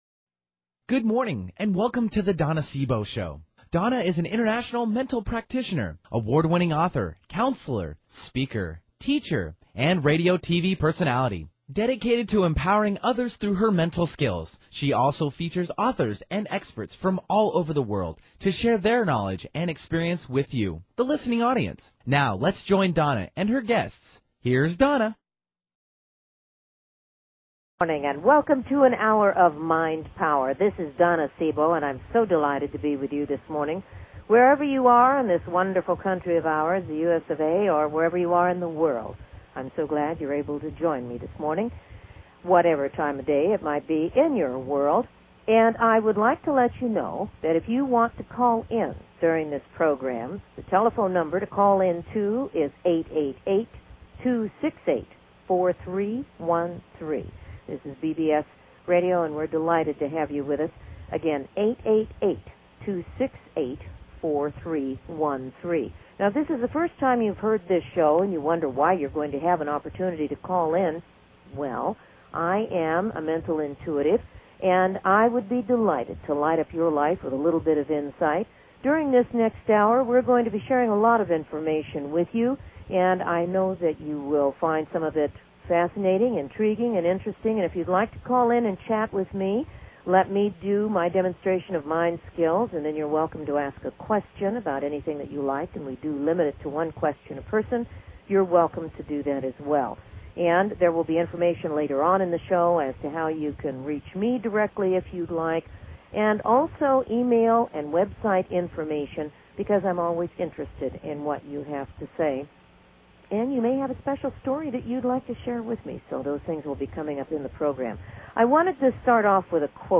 Talk Show Episode
Guests on her programs include CEO's of Fortune 500 companies to working mothers.
Callers are welcome to call in for a live on air psychic reading during the second half hour of each show.